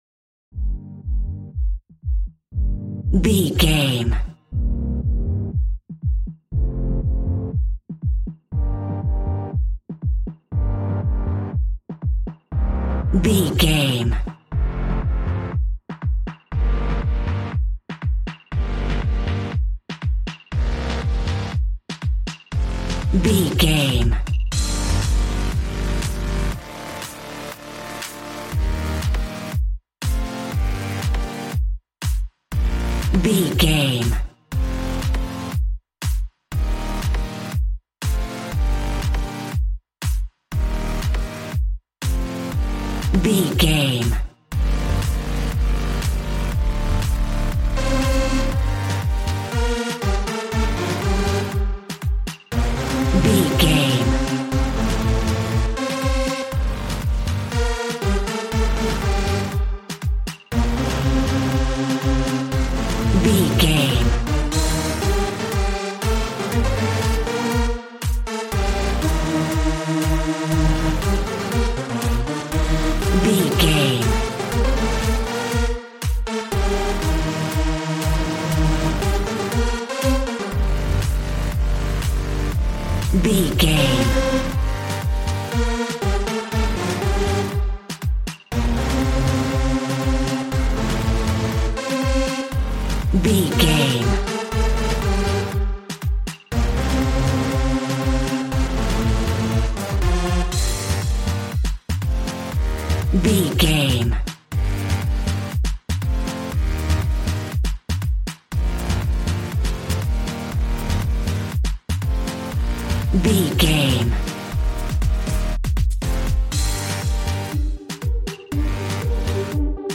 Aeolian/Minor
Fast
uplifting
lively
groovy
synthesiser
drums